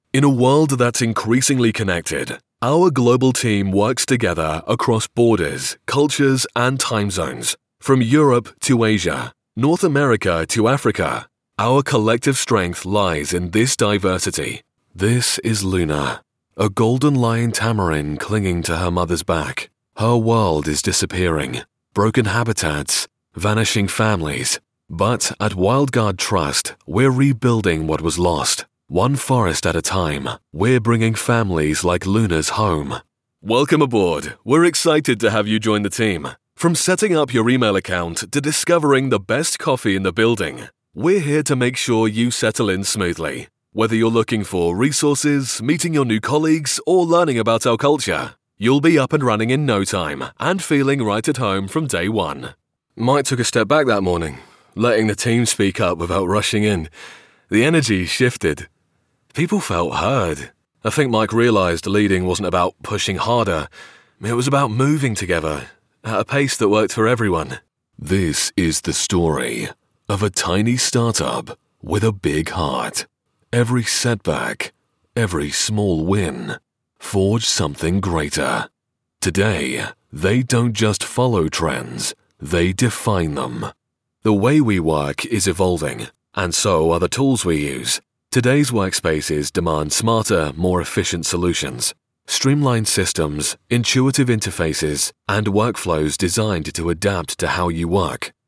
English (British)
Corporate Videos
Rode NT2A and NTG4
Vocal Booth To Go AVB4 sound booth